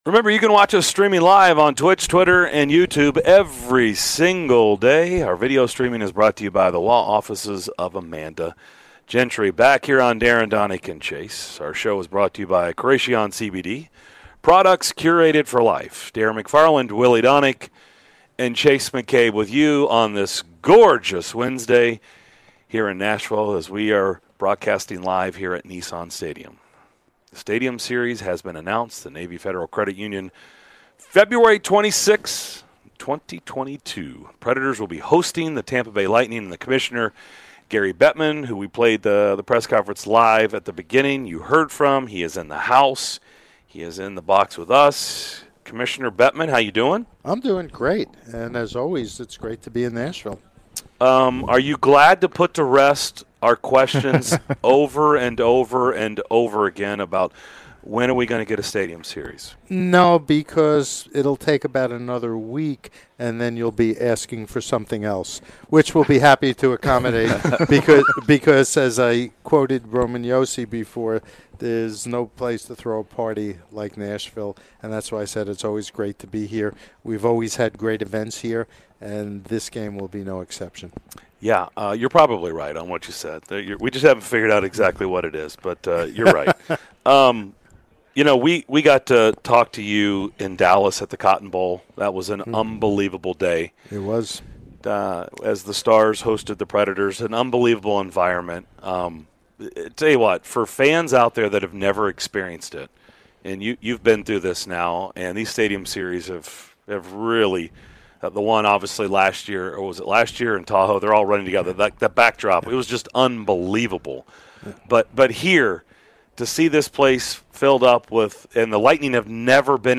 NHL Commissioner Gary Bettman joined the DDC to discuss the NHL Stadium Series coming to Nashville!